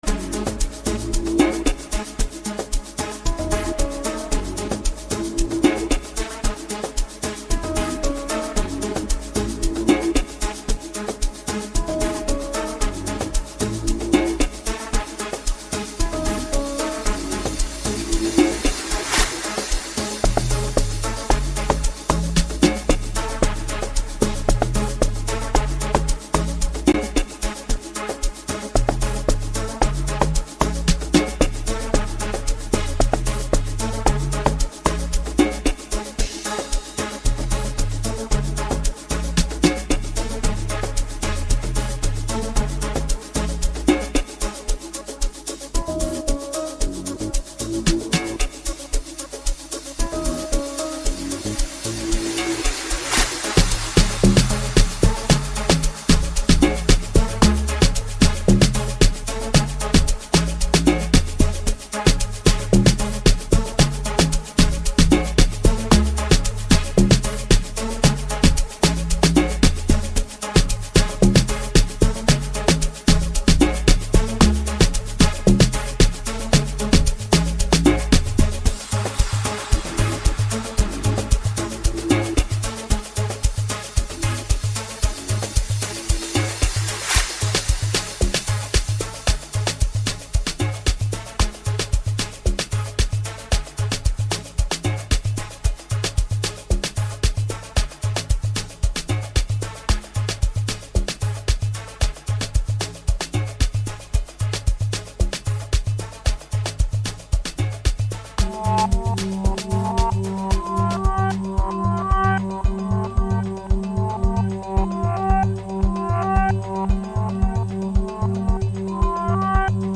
Soulful Piano Mix for the party attendees